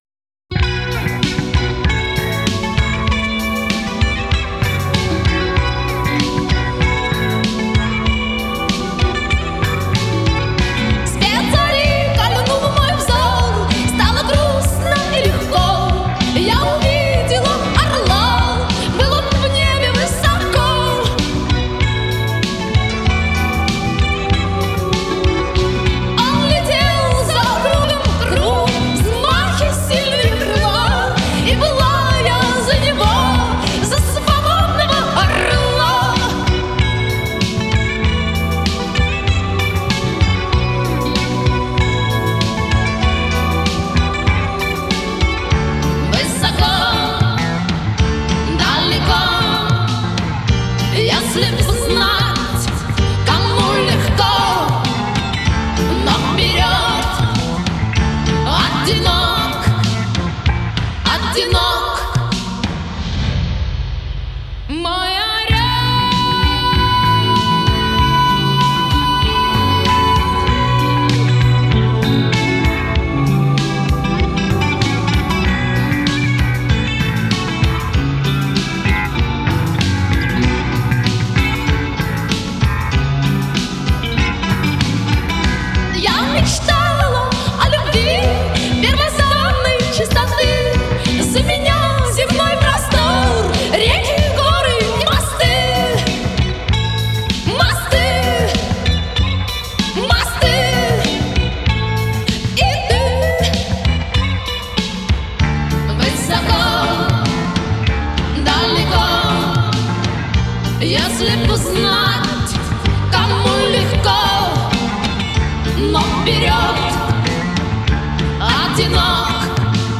Жанр: Rock , Rock & Roll